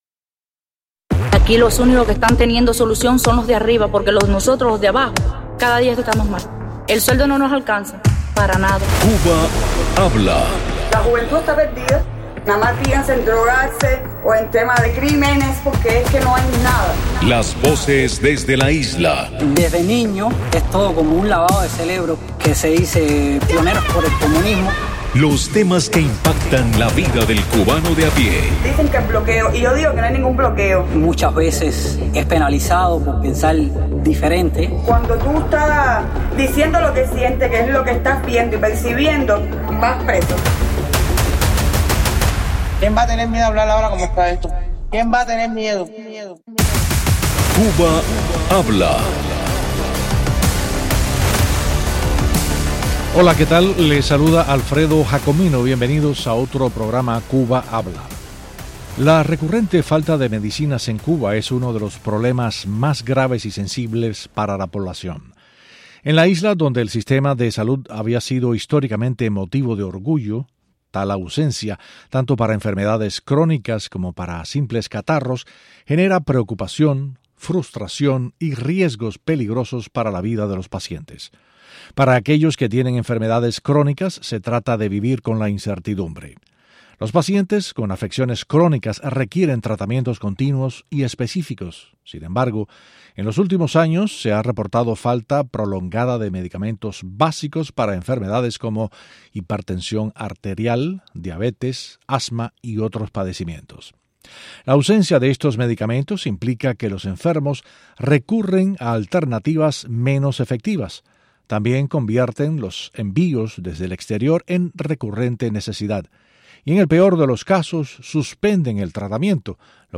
Cubanos dan testimonio de la escasez de medicamentos en la isla y las consecuencias que esto provoca en la ciudadanía.